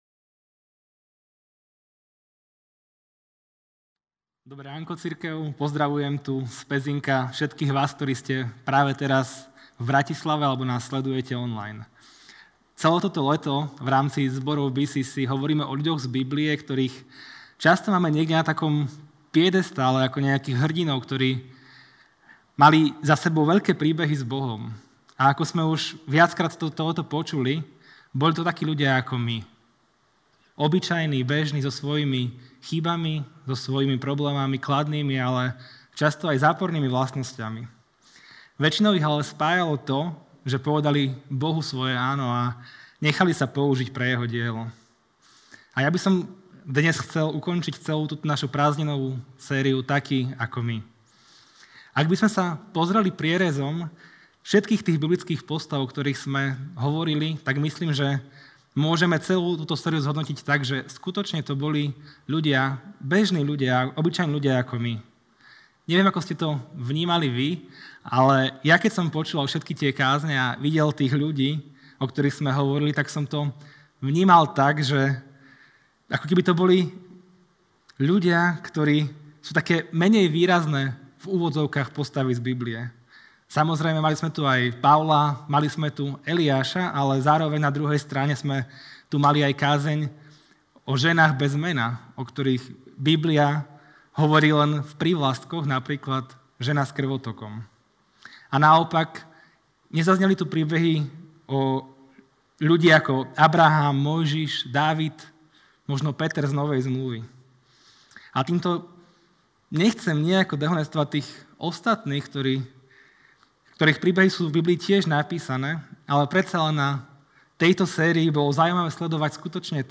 V poslednej kázni zo série TAKÍ, AKO MY